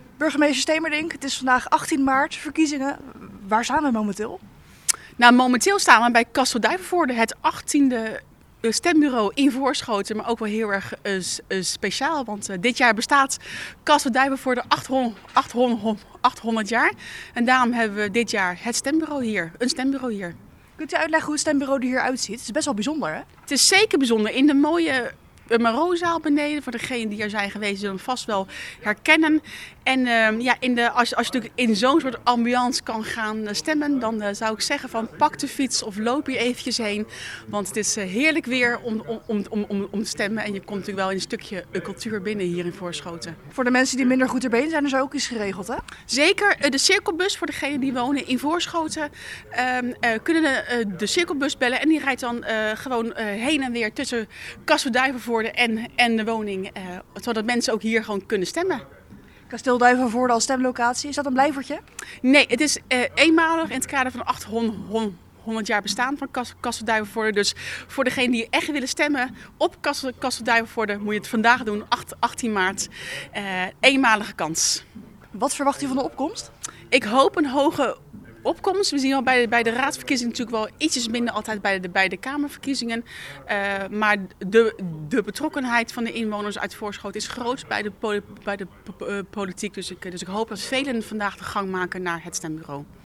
in gesprek met burgemeester Nadine Stemerdink: